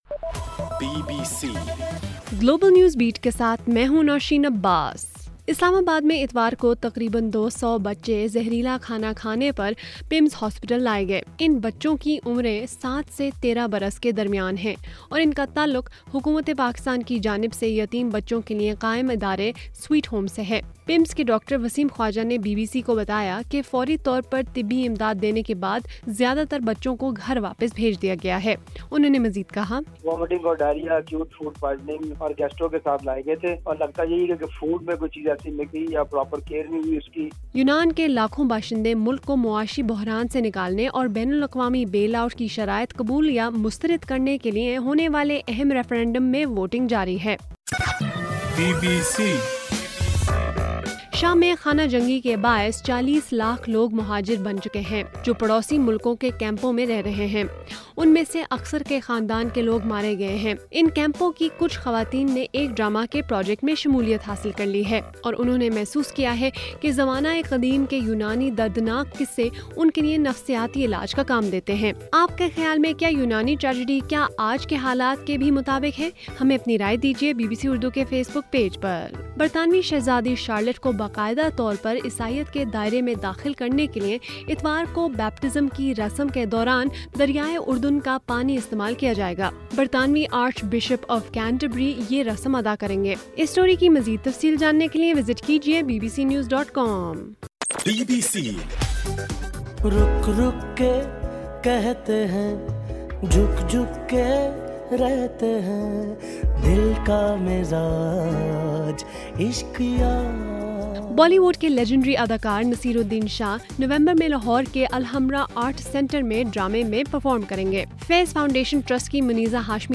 جولائی 5: رات 8 بجے کا گلوبل نیوز بیٹ بُلیٹن